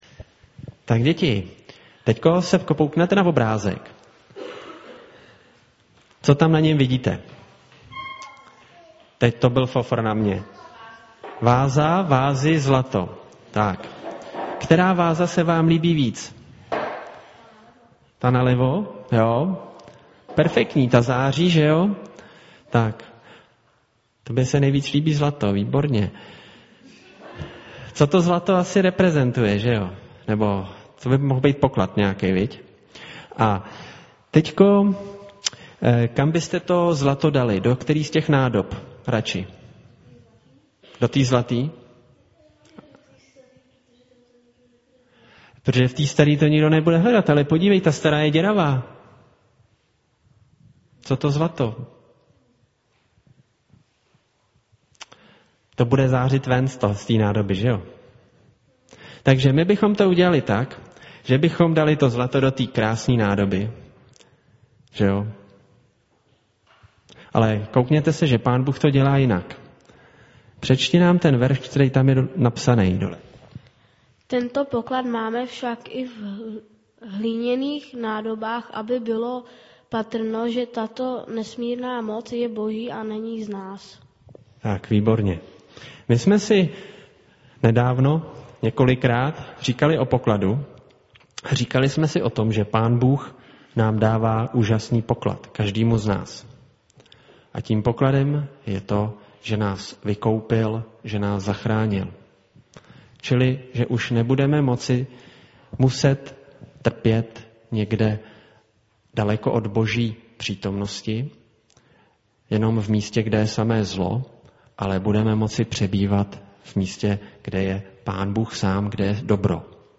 Nedělní bohoslužby Husinec přehrát